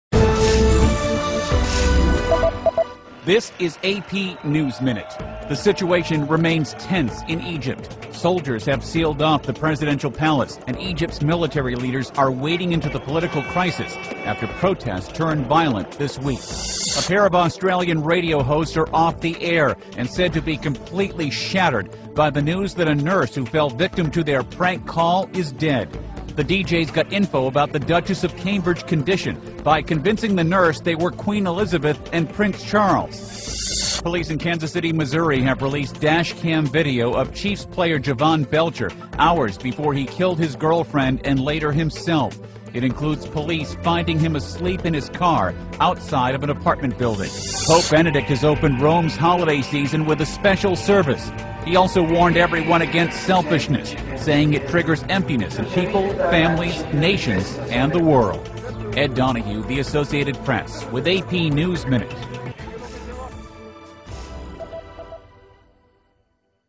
在线英语听力室美联社新闻一分钟 AP 2012-12-11的听力文件下载,美联社新闻一分钟2012,英语听力,英语新闻,英语MP3 由美联社编辑的一分钟国际电视新闻，报道每天发生的重大国际事件。电视新闻片长一分钟，一般包括五个小段，简明扼要，语言规范，便于大家快速了解世界大事。